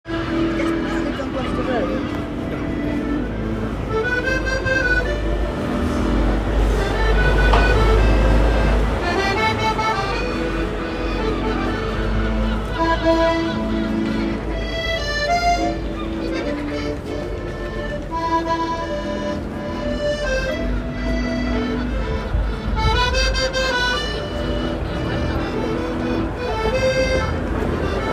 3. Accordion and seagulls